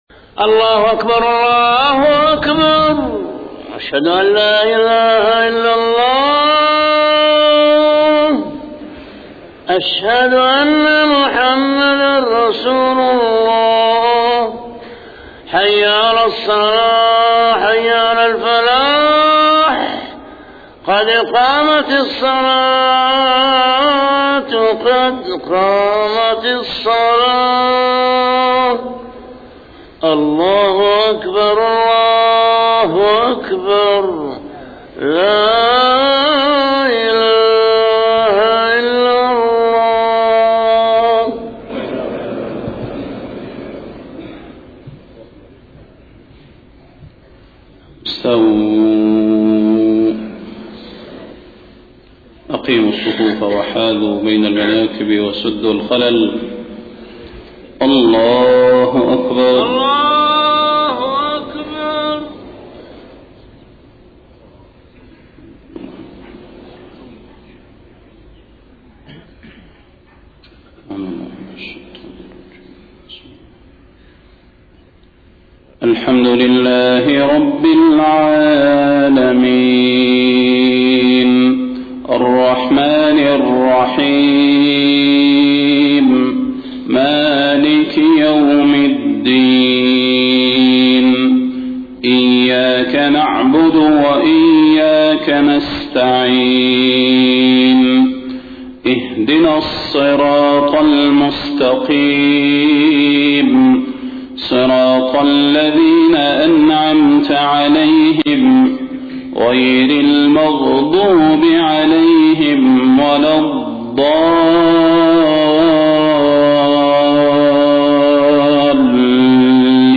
صلاة الفجر 27 صفر 1431هـ خواتيم سورة الشورى 36-53 > 1431 🕌 > الفروض - تلاوات الحرمين